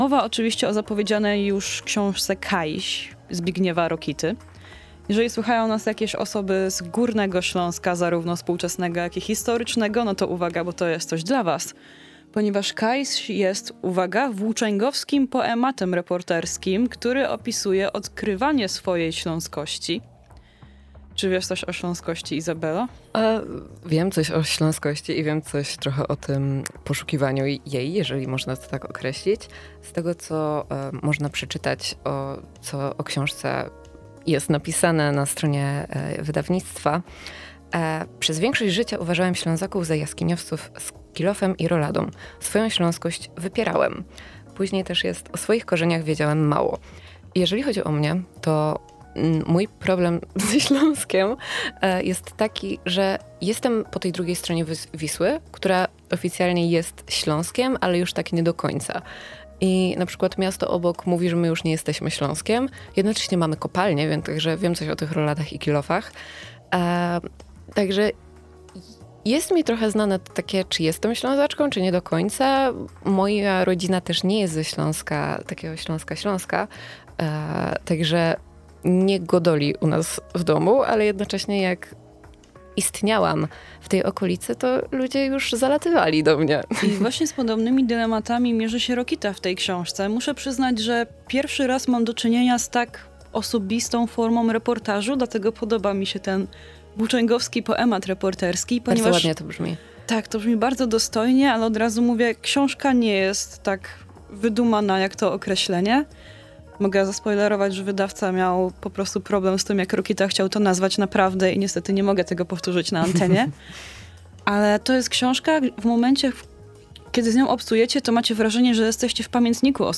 „Kajś” Zbigniewa Rokity – recenzja z Pełnej Kultury